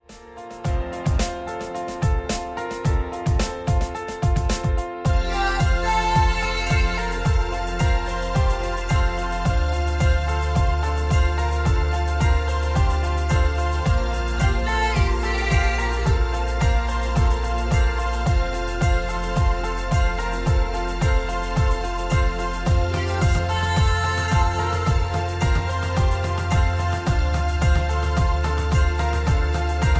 Backing track Karaoke
Pop, 2010s